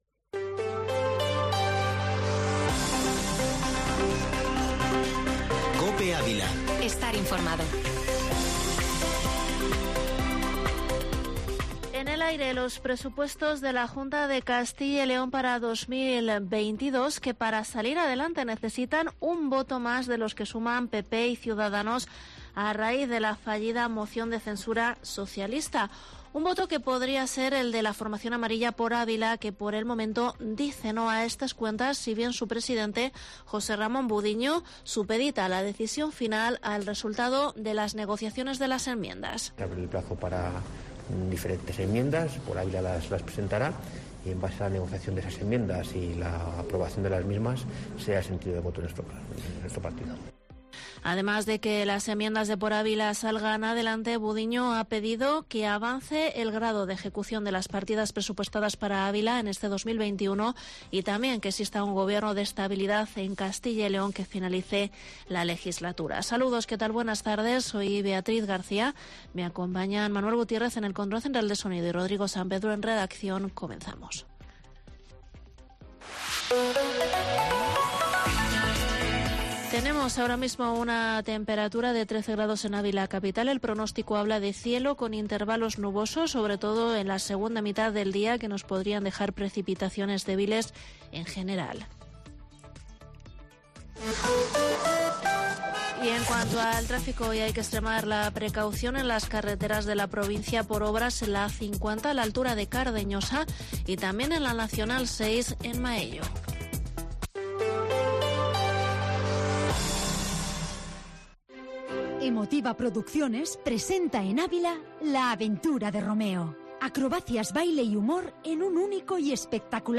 Informativo Mediodía Cope en Avila 2/11/2021